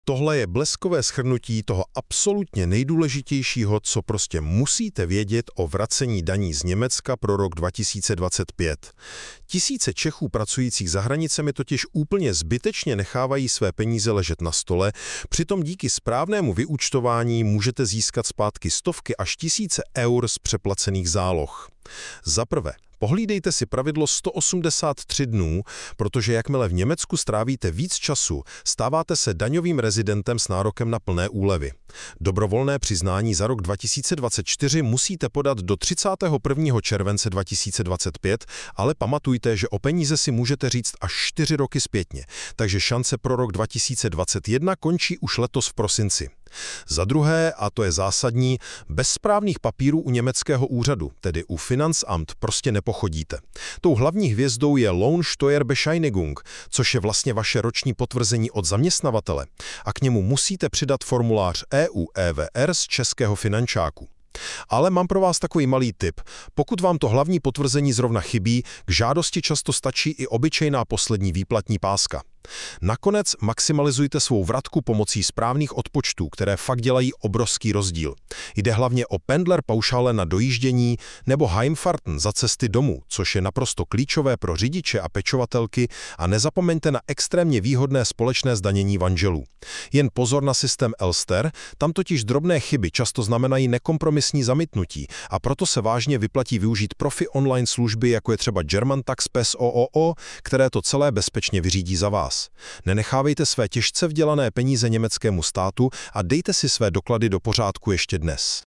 Popis audio souboru: Nahrávka obsahuje diskuzi o vrácení daní z Německa 2025 – jak získat přeplatky na daních krok za krokem.